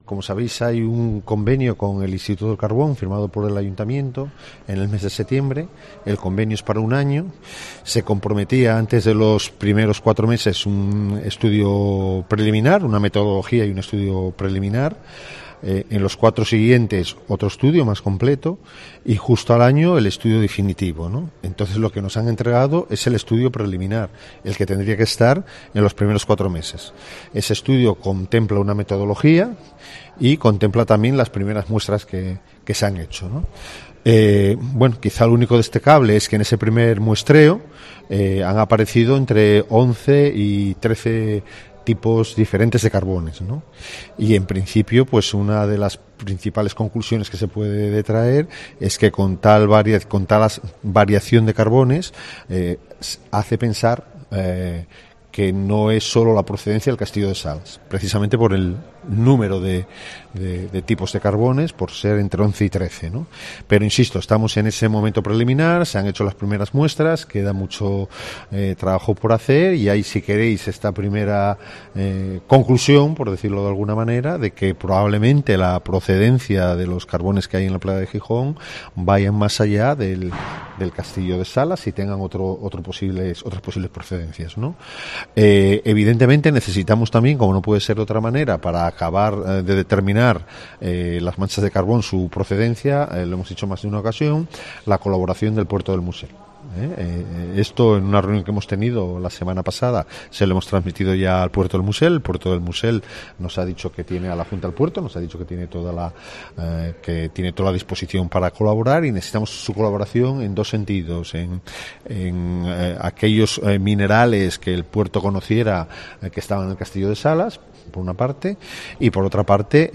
Aurelio Martín (Concejal de Medio Ambiente Ayto de Gijón) Sobre el carbón en la playa de San Lorenzo